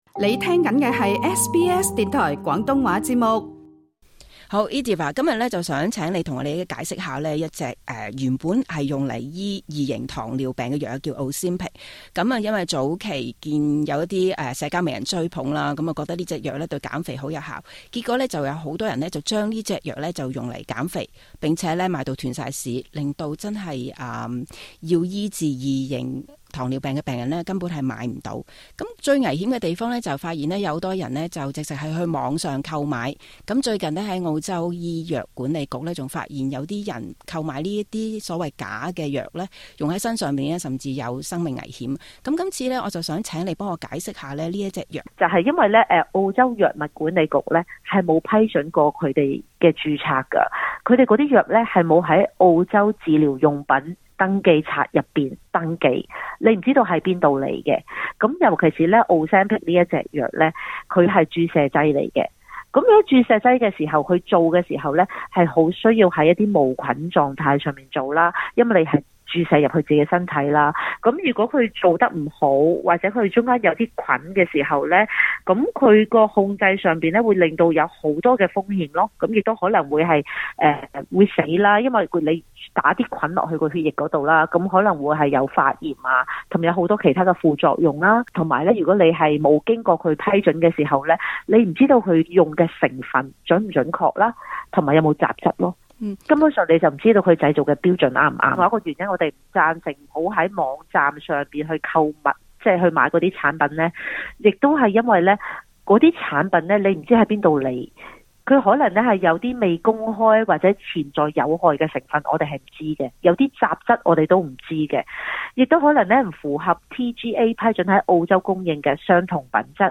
時事專訪